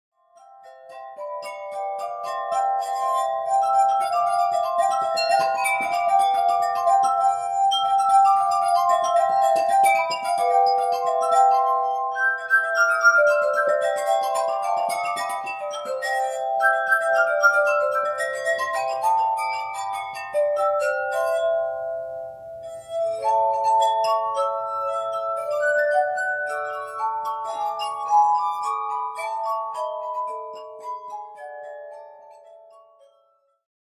Glass Music
glass harp